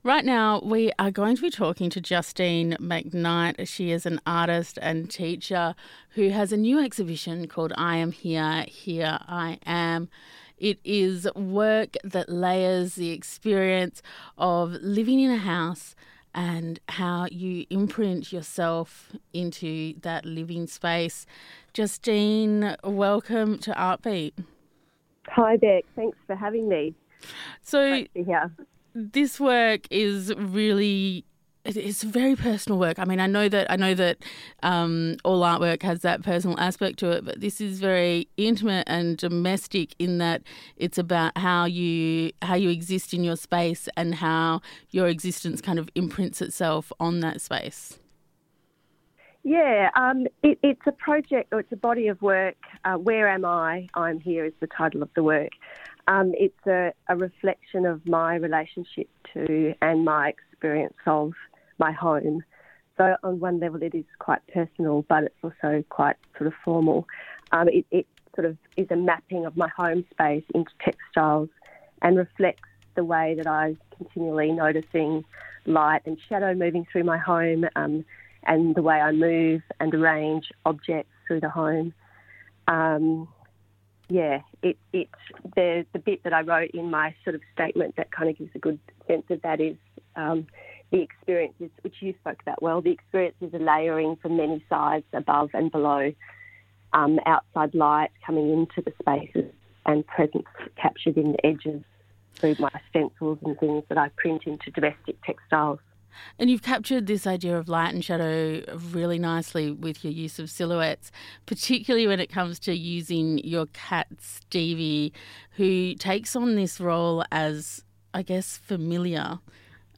Where Am I, I Am Here - Interview